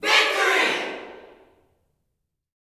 VICTOSHOUT.wav